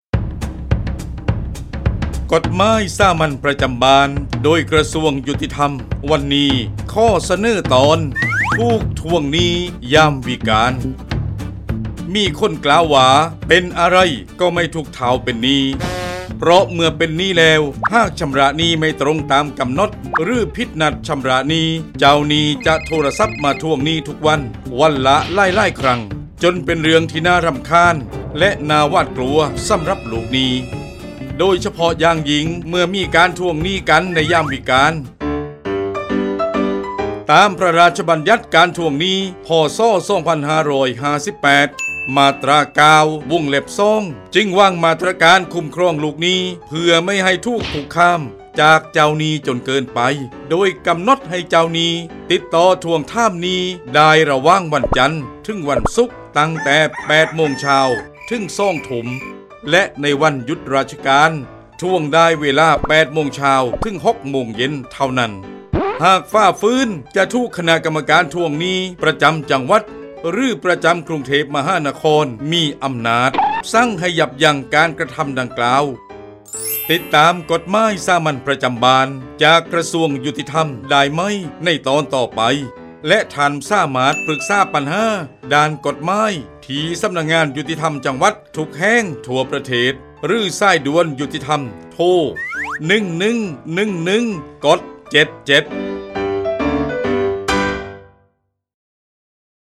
กฎหมายสามัญประจำบ้าน ฉบับภาษาท้องถิ่น ภาคใต้ ตอนถูกทวงหนี้ยามวิกาล
ลักษณะของสื่อ :   คลิปเสียง, บรรยาย